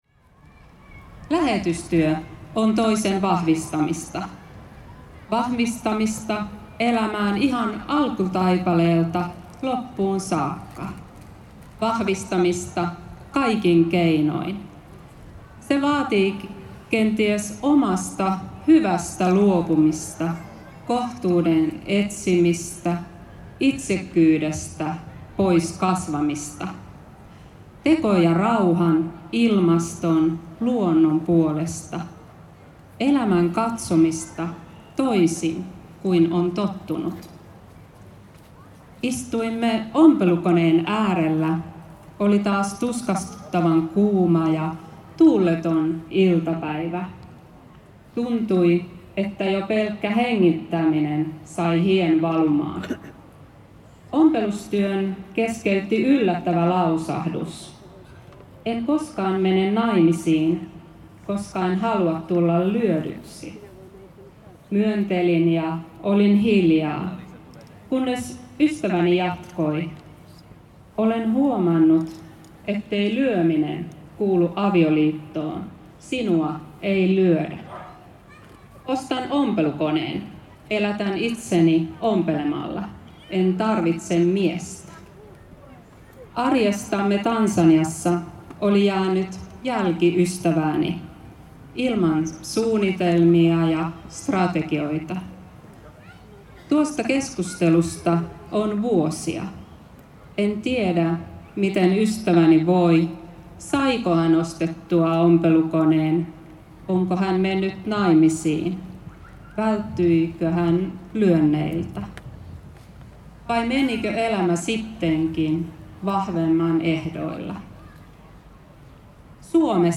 A missionary worker gives a speech at Herättäjäjuhlat festival in Tampere. The festival is the annual gathering of the religious Awakening movement.